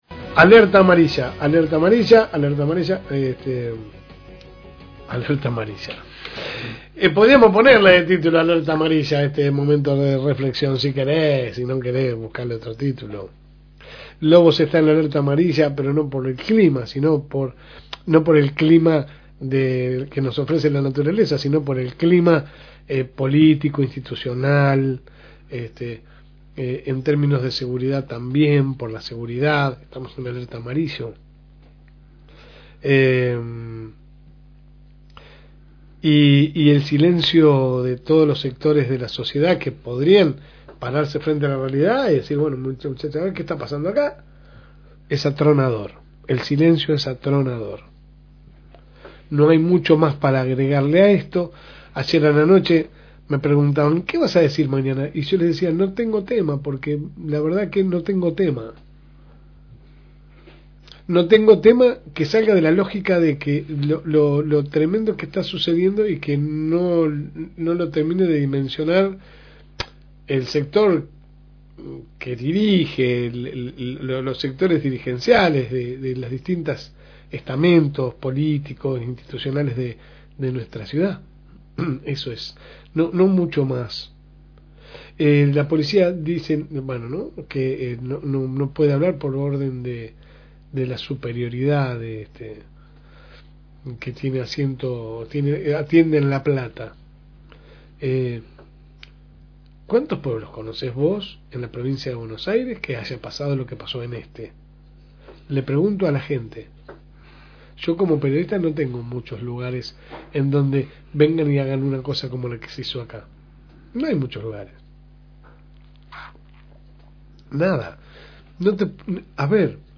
AUDIO – El editorial de La Segunda Mañana – FM Reencuentro